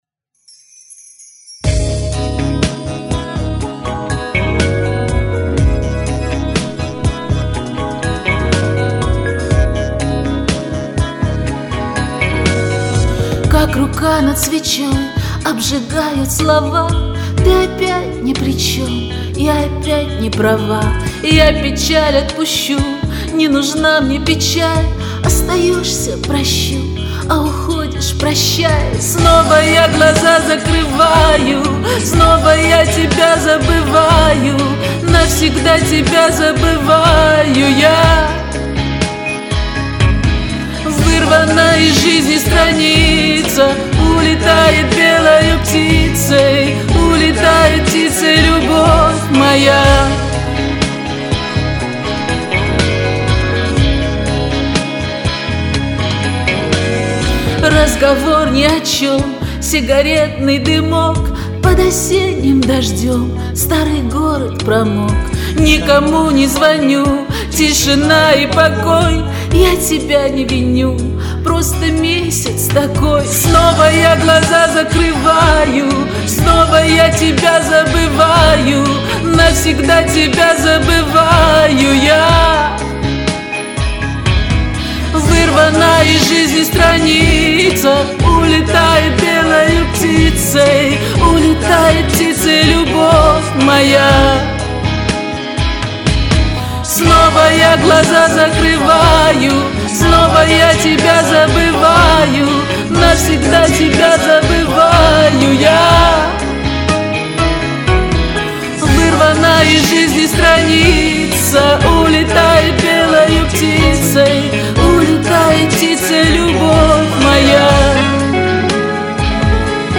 С вокалом у всех всё в порядке.
на надрыве. одинаково от и до, развития нет